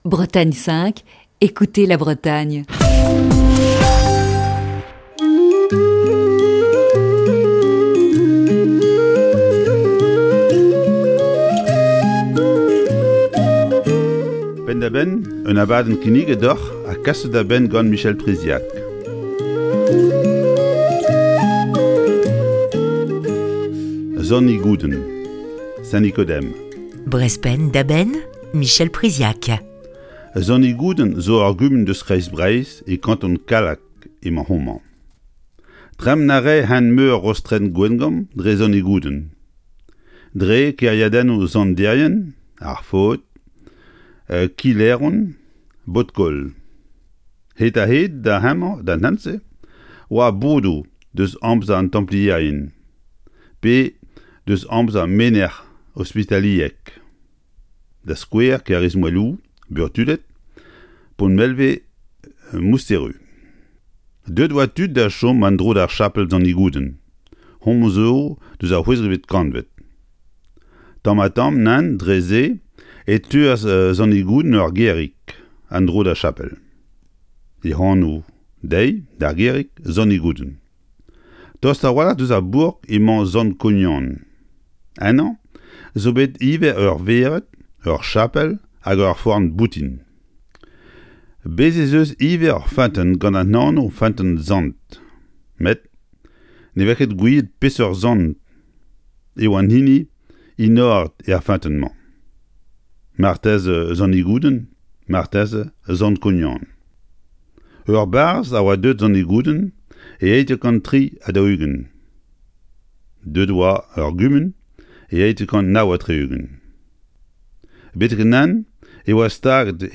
Chronique du 2 juin 2021.